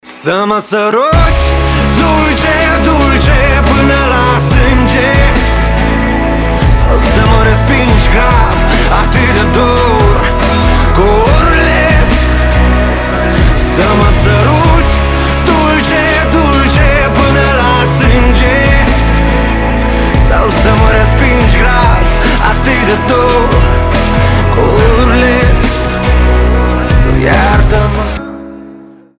• Качество: 320, Stereo
поп
грустные